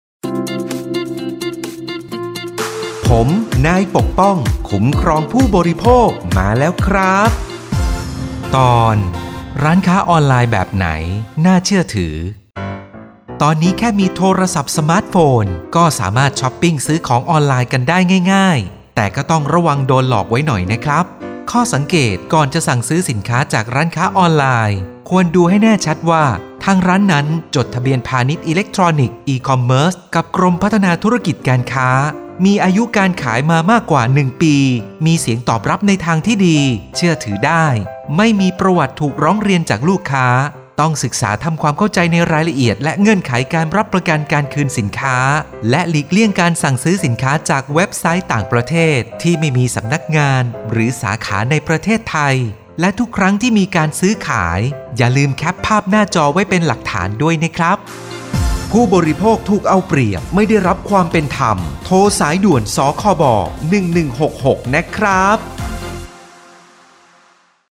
สื่อประชาสัมพันธ์ MP3สปอตวิทยุ ภาคกลาง
020.สปอตวิทยุ สคบ._ภาคกลาง_เรื่องที่ 20_.mp3